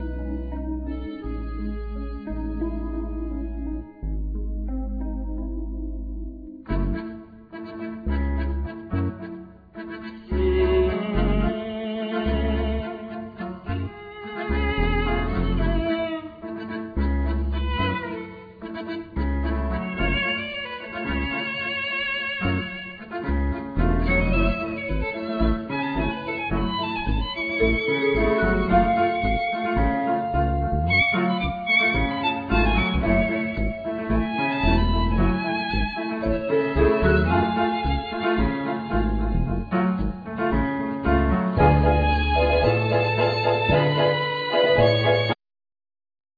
Accordeon,Vocal
Violin,Viola
Keyboards,Vocals
Electric & Acoustic Bass,Guiro
Vibraphone,Marimba,Glockenspiel,Percussion
Drums,Percussion